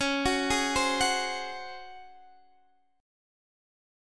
C#M7sus4 chord